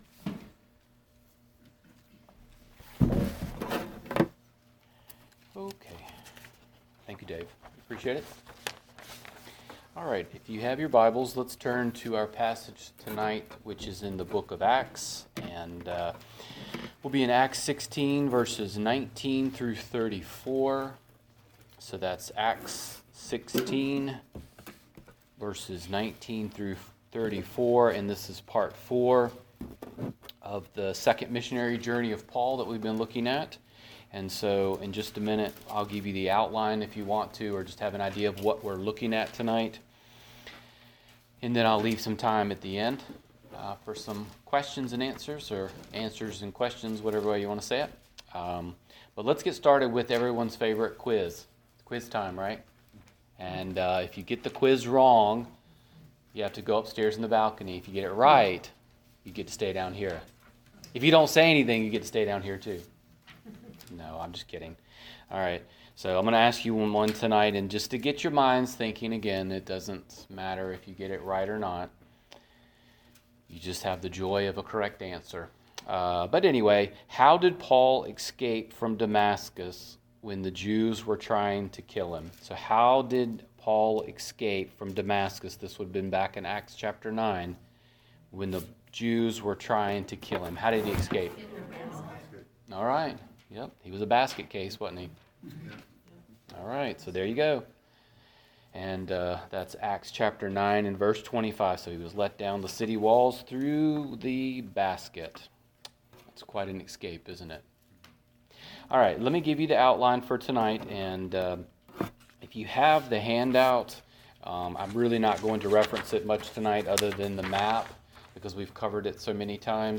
The Second Missionary Journey #4 - Wed. Night Bible Study